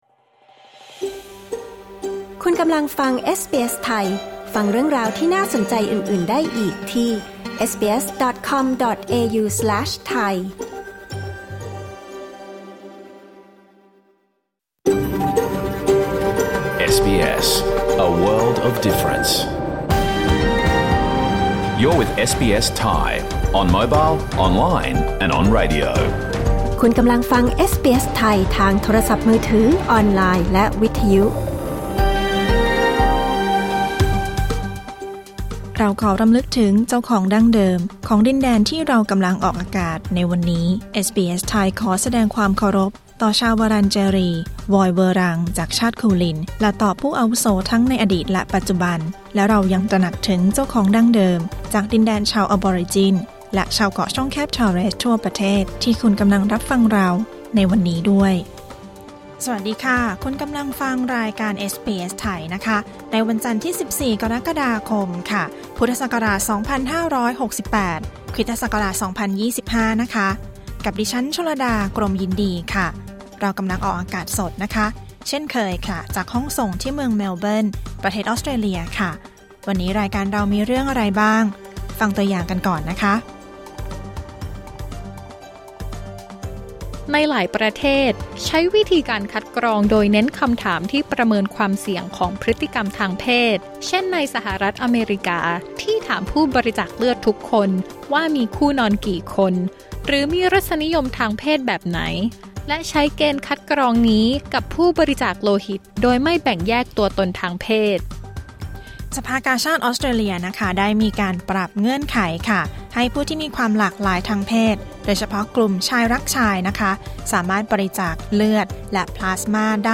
รายการสด 14 กรกฎาคม 2568